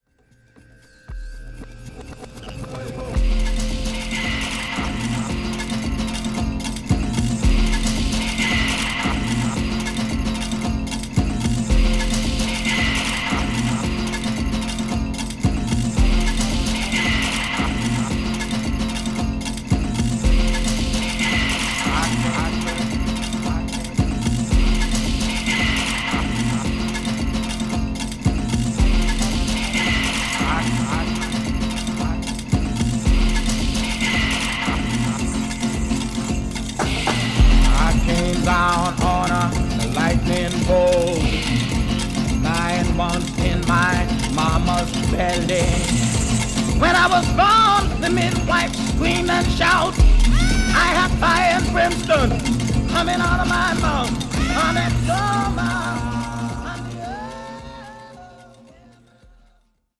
SOUL / FUNK / RARE GROOVE / DISCO